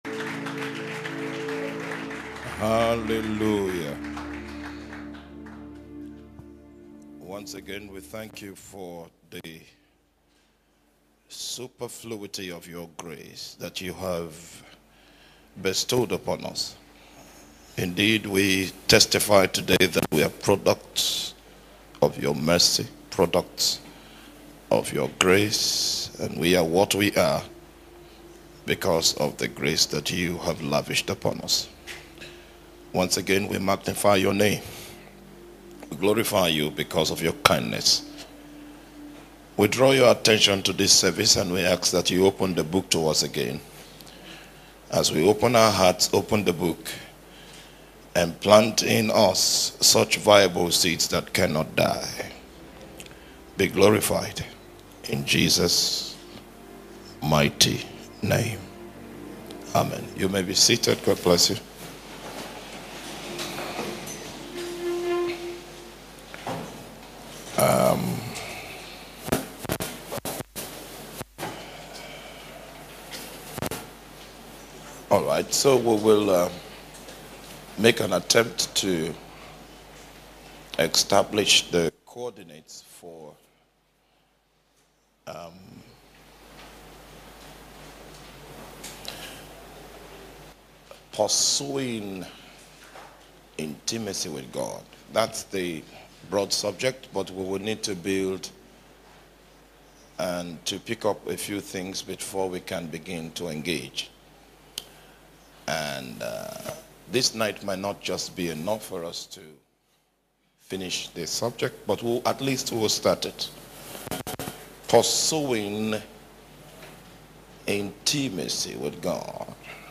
Message
A Teaching on How to Know God in a Deeper Dimension